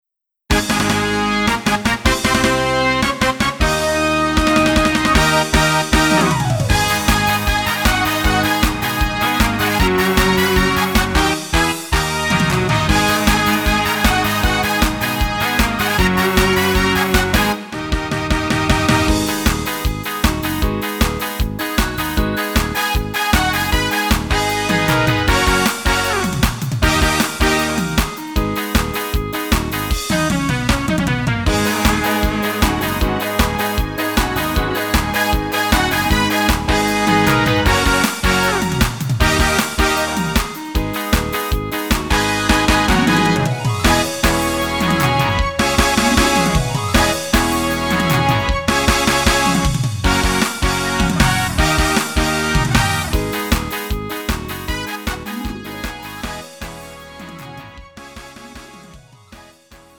음정 -1키 3:28
장르 가요 구분 Lite MR